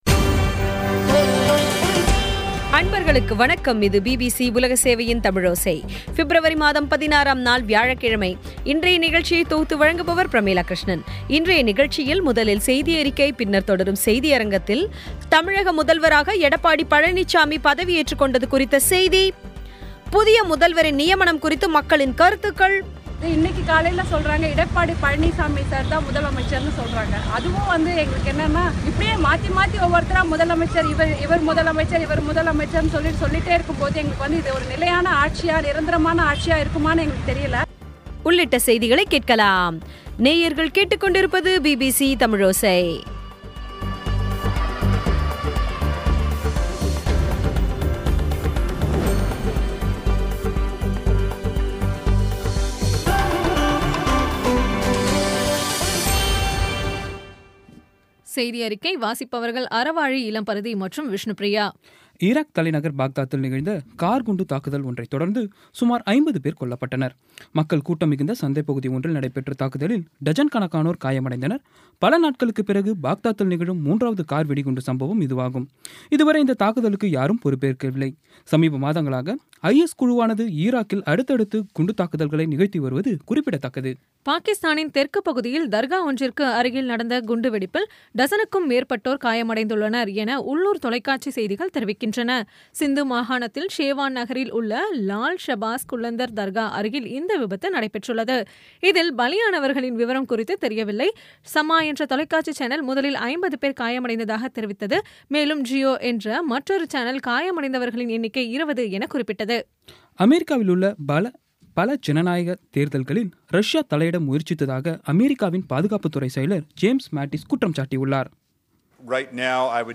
இன்றைய தமிழோசையில், முதலில் செய்தியறிக்கை, பின்னர் தொடரும் செய்தியரங்கத்தில்,தமிழக முதல்வராக எடப்பாடி பழனிச்சாமி பதவிஏற்றுக்கொண்டது குறித்த செய்தி புதிய முதல்வரின் நியமனம் குறித்து மக்களின் கருத்துக்கள் ஆகியவை கேட்கலாம்